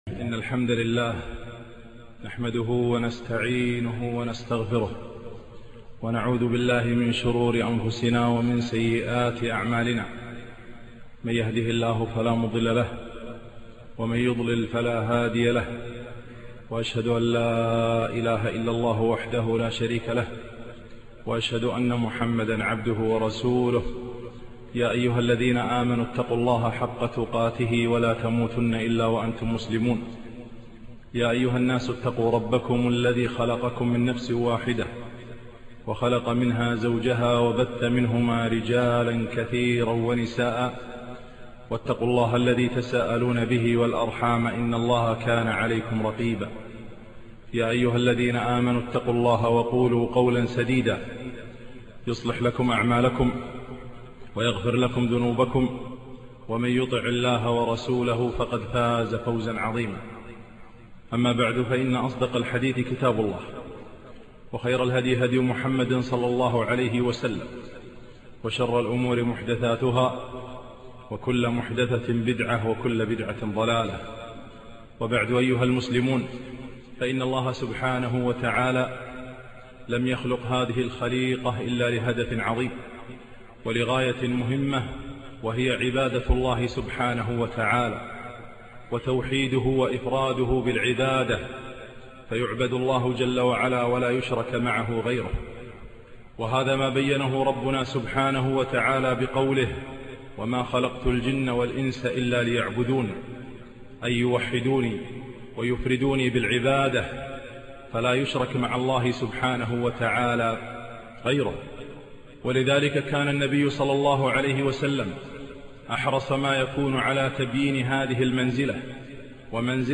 خطبة - الذ شيئ في الحياة